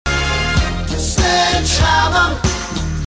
sledgehammer.mp3